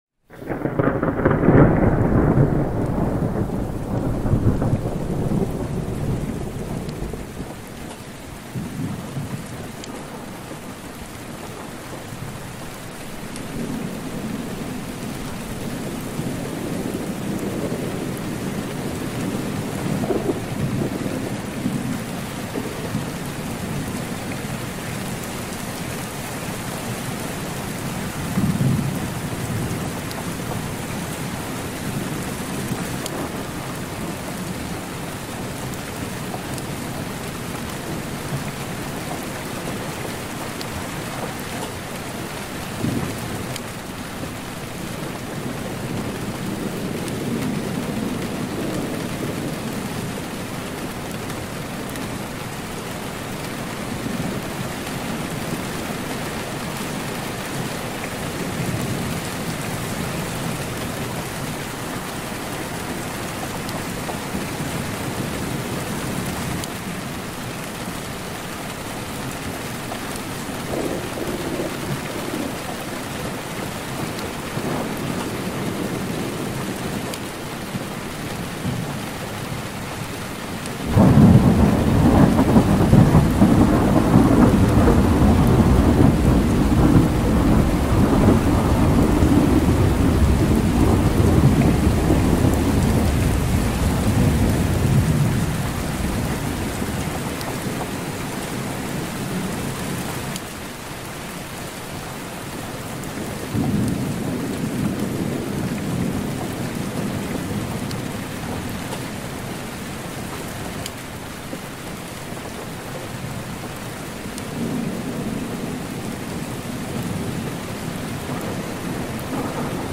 Window Thunderstorm Rain – Heavy Rain Sounds for Sleeping
Every episode of Rain Sounds is carefully crafted to deliver high-quality ambient rain recordings that promote deep sleep, reduce anxiety, and enhance mindfulness.
Whether you love the steady rhythm of a night storm, the calming drip of rain on a window, or the soft patter of distant thunder, Rain Sounds brings nature&rsquo